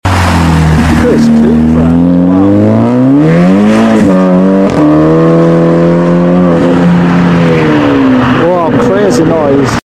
Stage 2+ Ibiza Cupra Accelarating